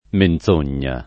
menZ1n’n’a] s. f. — abbastanza forte, in Tosc. e in tutta l’It. centr., una tendenza recente alla pn. sonora della -z- (favorita prob. da un uso della voce sempre più limitato a casi gravi e solenni, fuori del discorso di tutti i giorni) — cfr. sogno